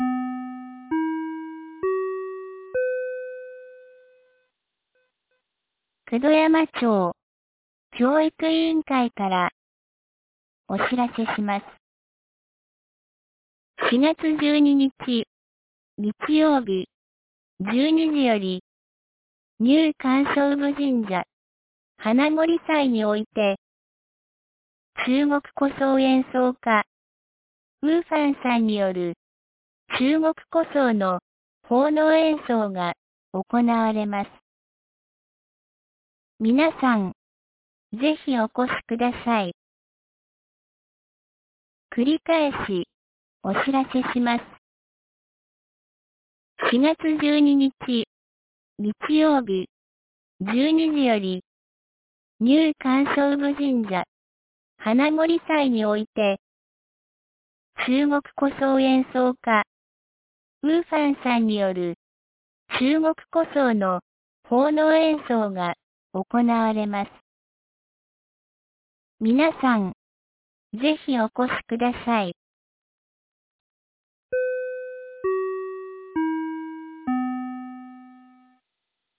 2026年04月10日 10時01分に、九度山町より全地区へ放送がありました。